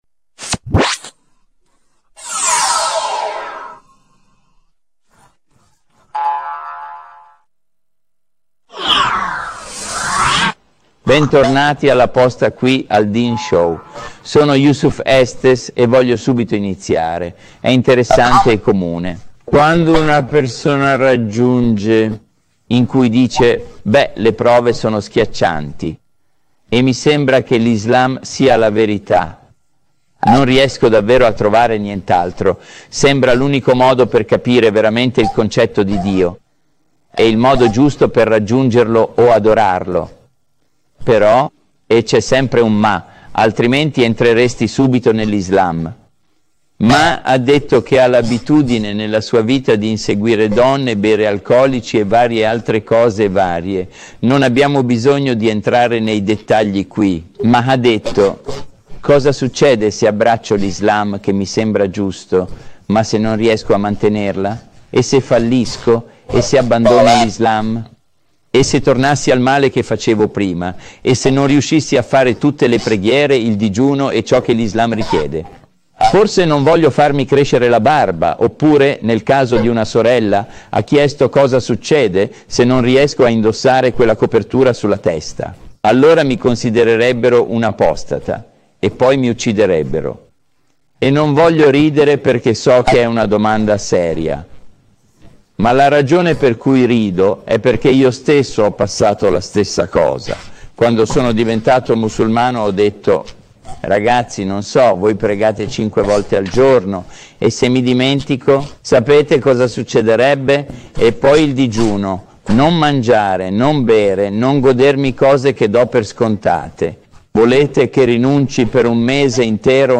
conferenza